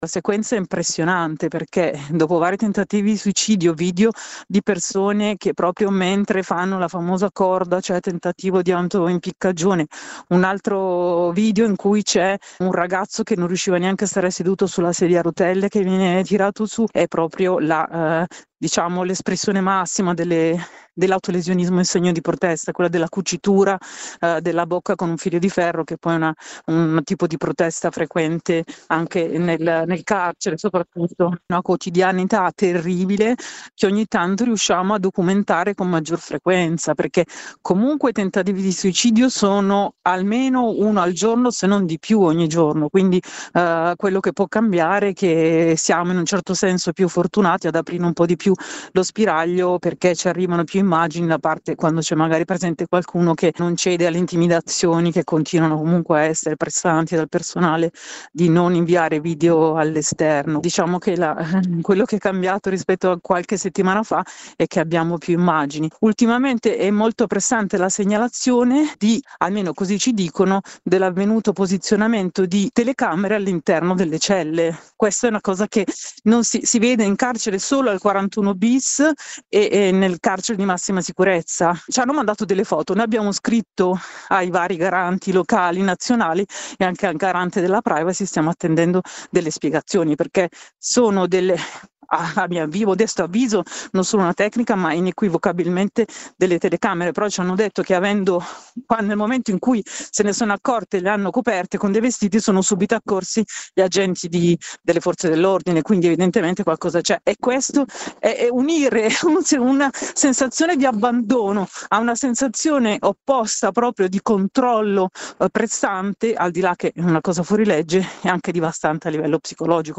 La rete Mai più lager – No ai Cpr ha denunciato gli ultimi fatti tragici e segnalato quella che sembra la presenza di telecamere di videosorveglianza all’interno delle celle di via Corelli. La testimonianza